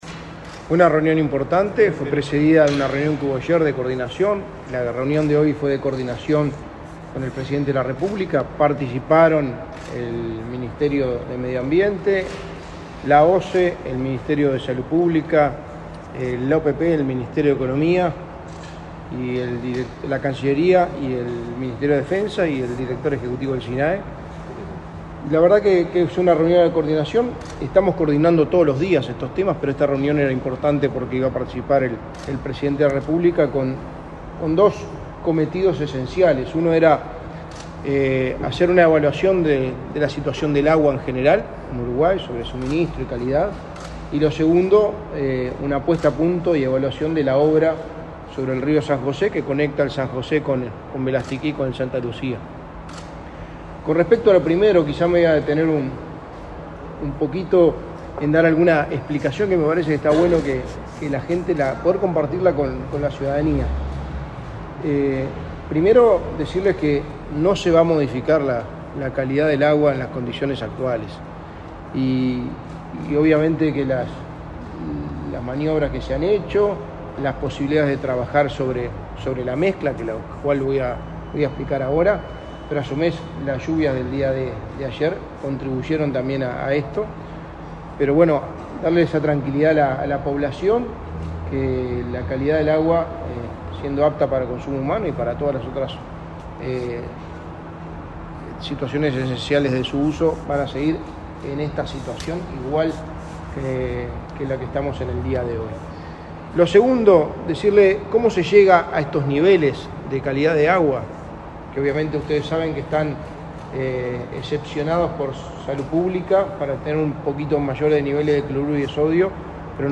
Declaraciones a la prensa del secretario de la Presidencia, Álvaro Delgado
Declaraciones a la prensa del secretario de la Presidencia, Álvaro Delgado 05/07/2023 Compartir Facebook X Copiar enlace WhatsApp LinkedIn El presidente de la República, Luis Lacalle Pou, recibió, este 5 de julio, a las autoridades del Poder Ejecutivo y de OSE, para analizar la situación del abastecimiento de agua potable en la zona metropolitana. Tras la reunión, el secretario de la Presidencia, Álvaro Delgado, informó a la prensa.